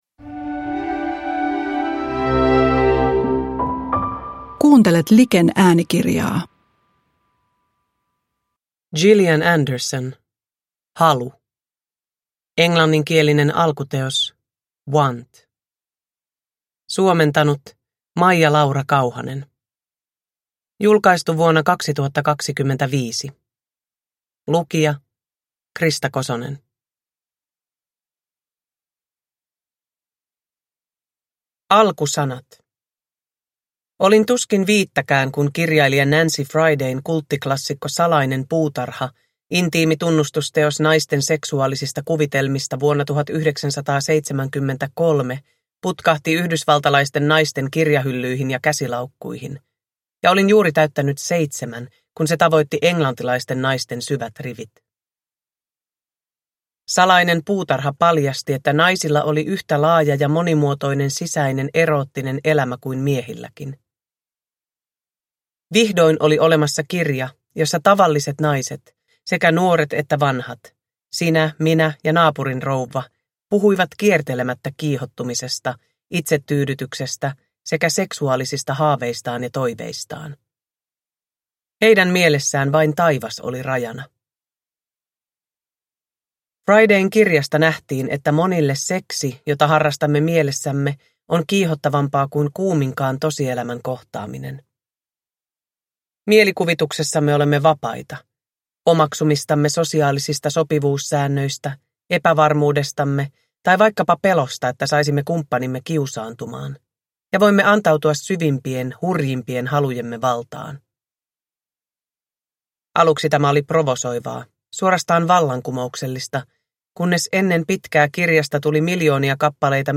Halu – Ljudbok
Uppläsare: Krista Kosonen